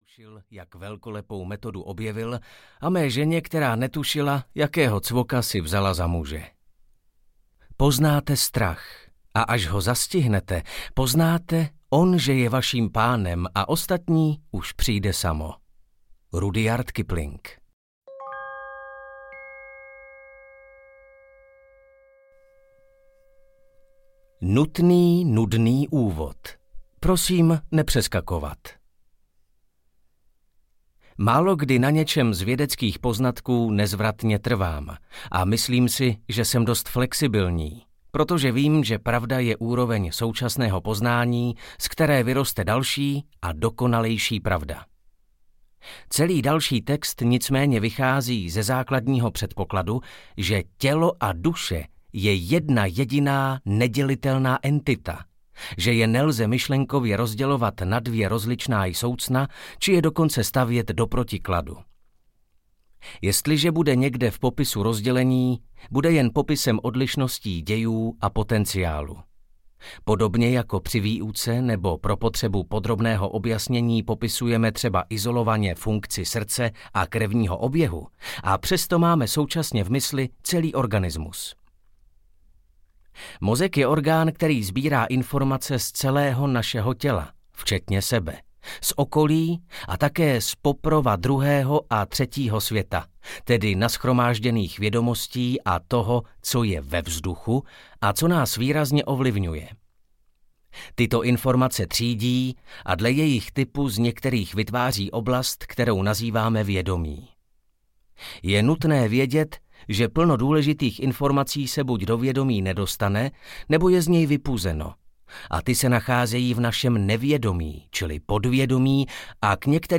Strach a úzkost a jak na ně audiokniha
Ukázka z knihy